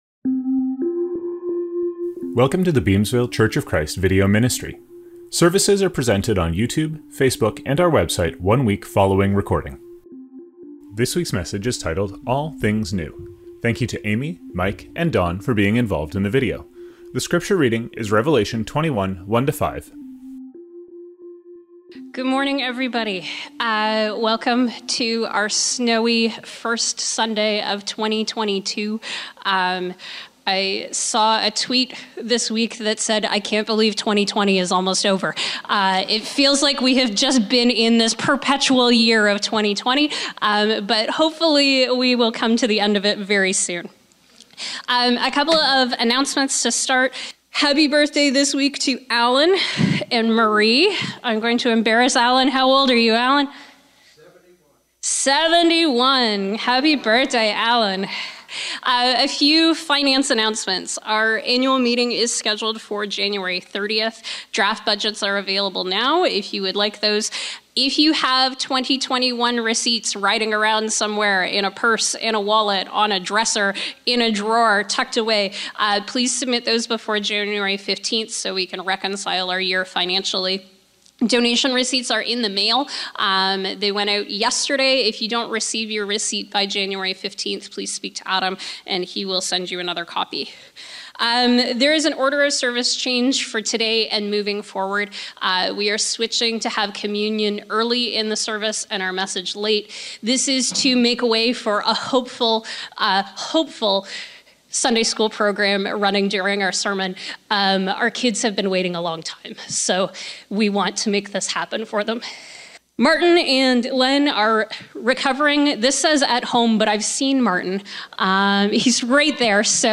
Scriptures from this service include: Welcome – Revelation 21:1-5.